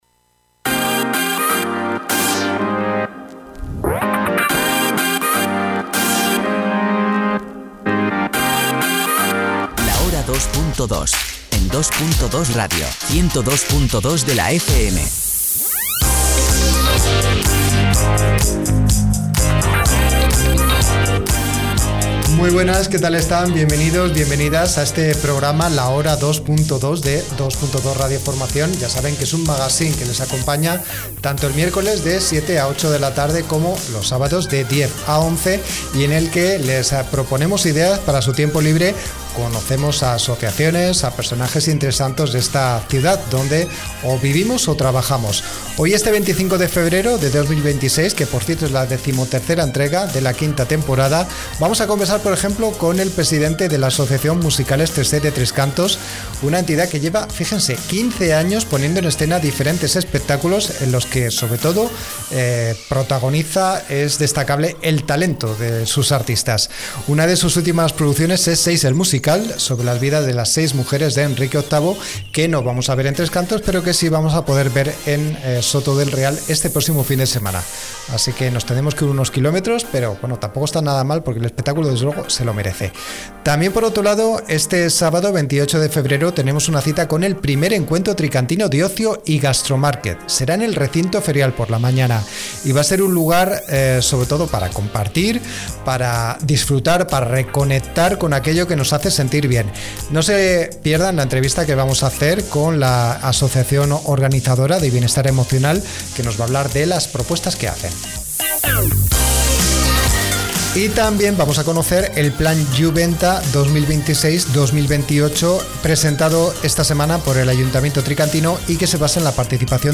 Hoy 25 de febrero de 2026 es la decimo tercera entrega de la quinta temporada del magazine La Hora 2.2 de Dos.Dos Radio Formación. a partir de ahora y durante una hora vamos a hablar de los diferentes temas que les contamos ahora desarrollados por los integrantes del taller de radio que hacemos en Factoría […]